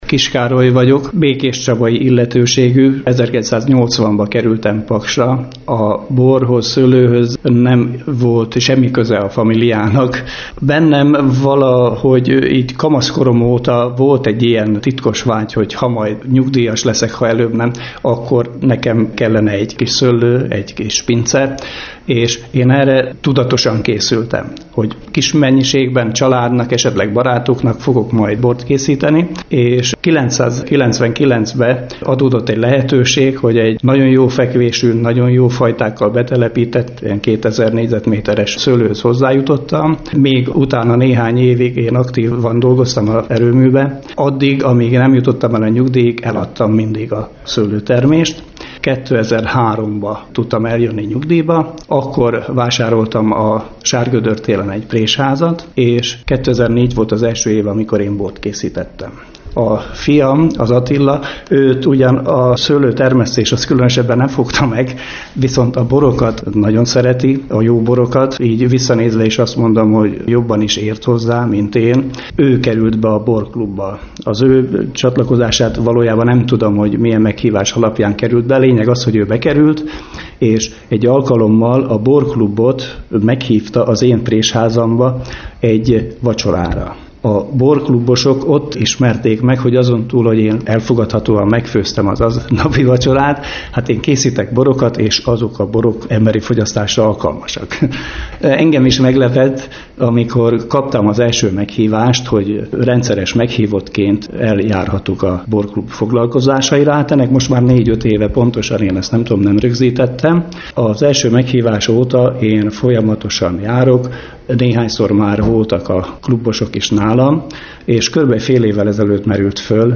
interjú